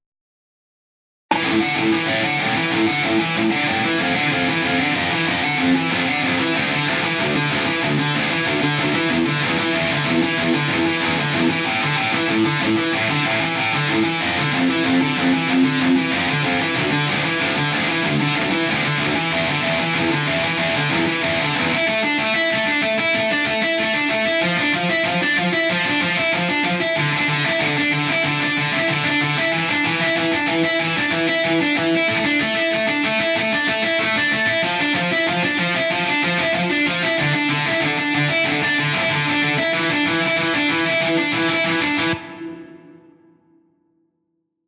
Gitarrist für Gitarrenriff
Finde ich vom Ansatz her besser als dieses feine Jazzgeplänkel - mit anderen Worten, ich hab' mal versucht, deutlich mehr reinzudreschen, was anschlagstechnisch zwar nicht wirklich schön ist - und einen anderen Sound hätte ich auch suchen müssen, wollte ich aber gerade nicht mehr, um nur die Idee zu demonstrieren sollte es reichen. Ist halt einfach deutlich punkiger, speziell die hohe Abteilung - die halt, anders als diese zarte Gutenachtliedzupferei eben ordentlich reinschranzt und deshalb nicht mehr so abfällt.